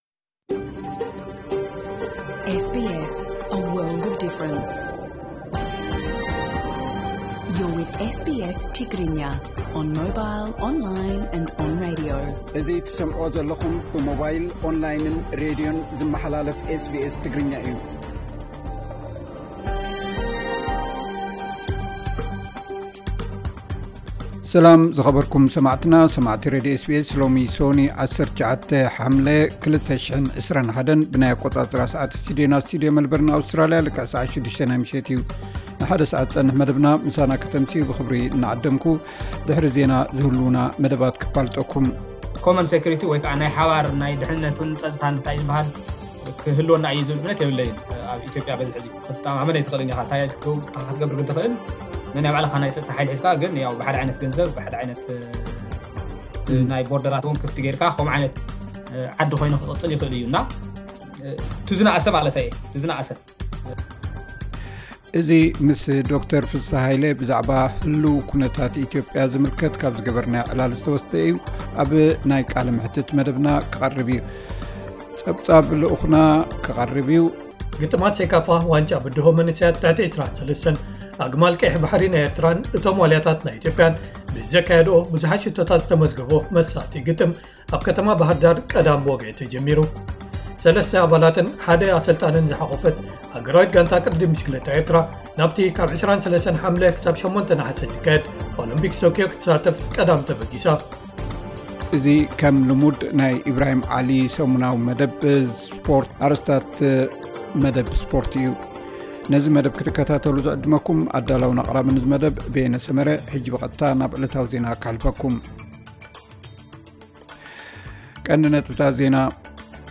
ዕለታዊ ዜና 19 ሓምለ 2021 SBS ትግርኛ